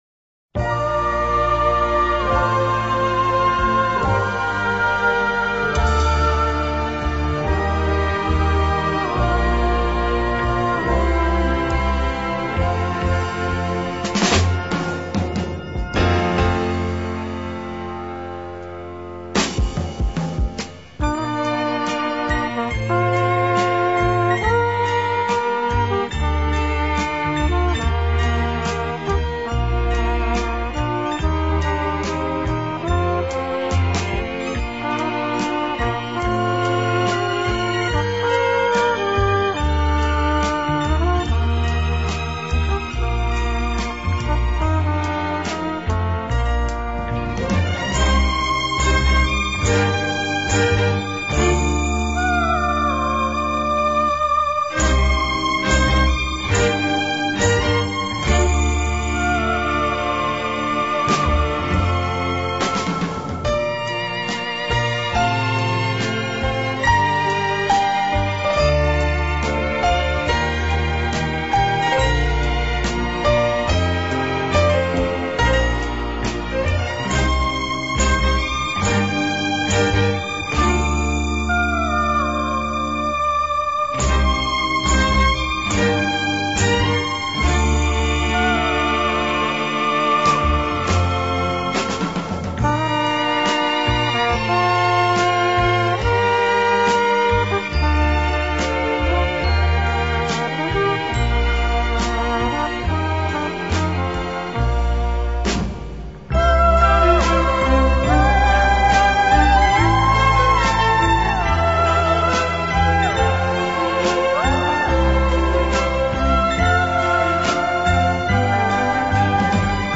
音樂類型: 演奏音樂